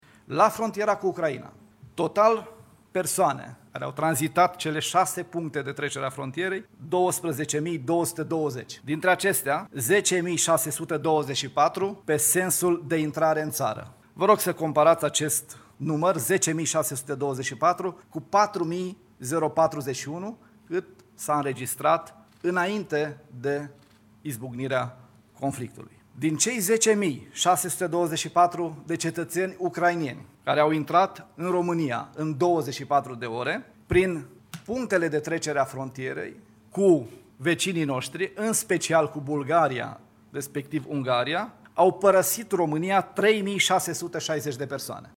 Aproape 10.000 de ucraineni au intrat în ultimele 24 de ore în România, din care 7.000 au rămas în țara noastră. Precizările au fost făcute de ministrul de Interne, Lucian Bode.